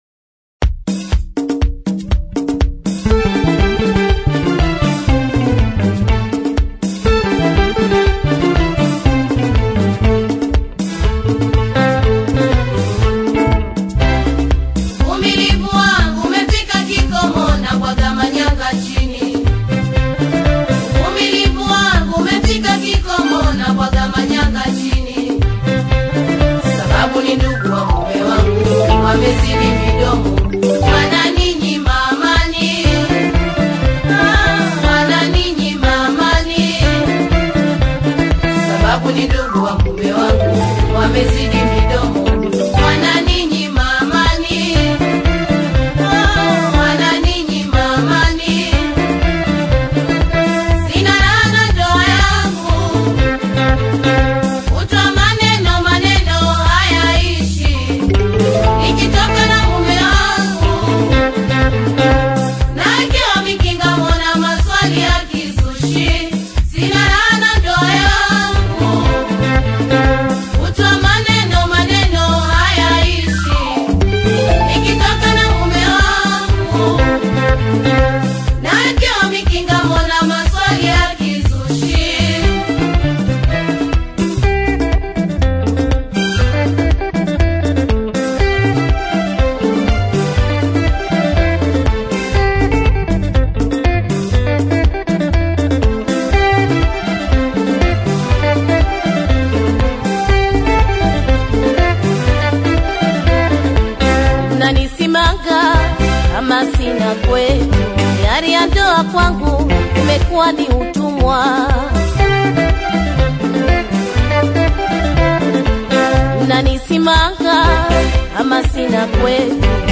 melodic performance